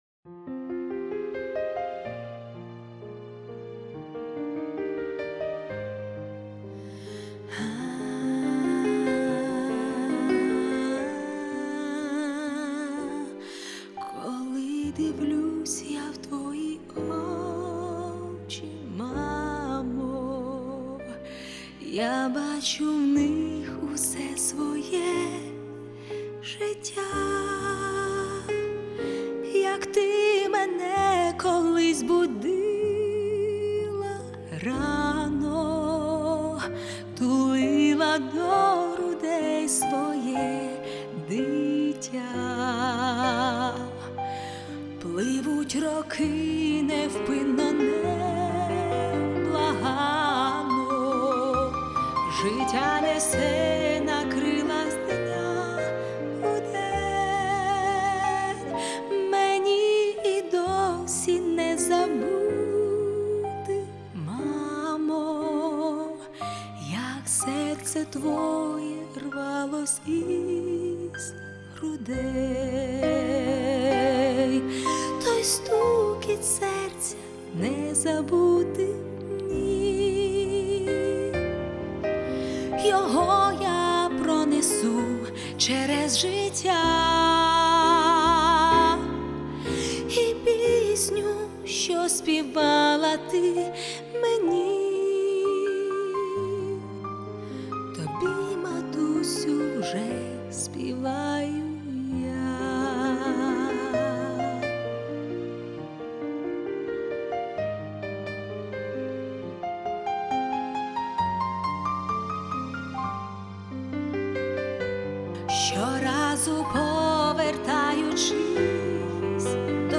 песня
419 просмотров 573 прослушивания 20 скачиваний BPM: 80